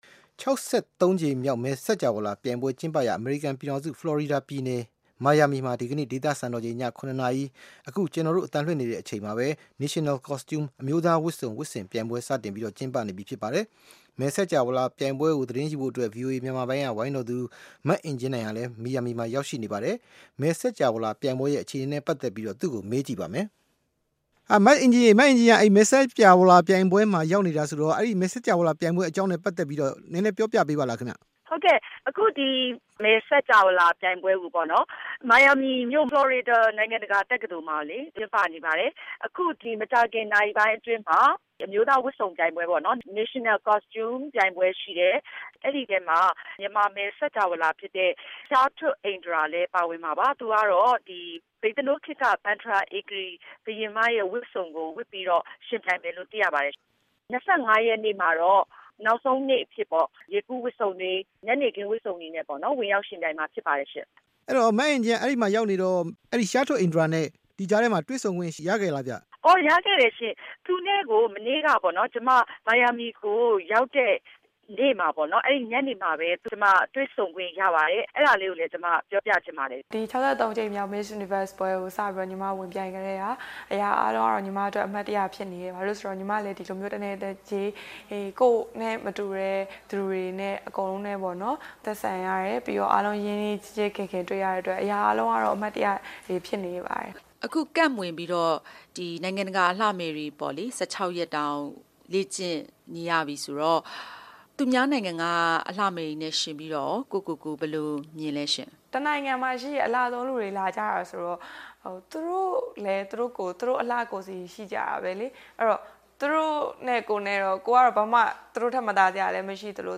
တွေ့ဆုံမေးမြန်းခန်း